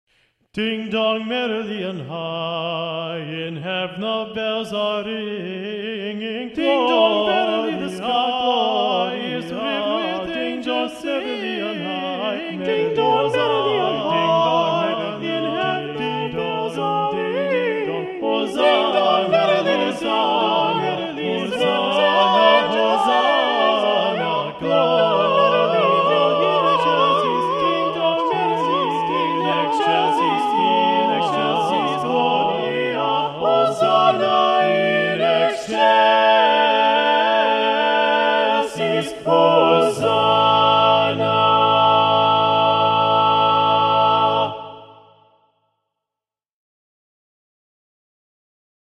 Choral Music
Choral ~ Holiday ~ A Cappella
(Choral fanfare)